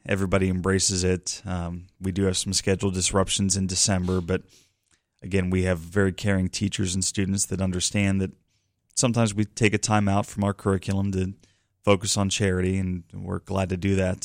On Indiana in the Morning on WCCS this morning, representatives from the Indiana Senior High Student Government Association stopped by to make a donation of $7,000 to the fund drive.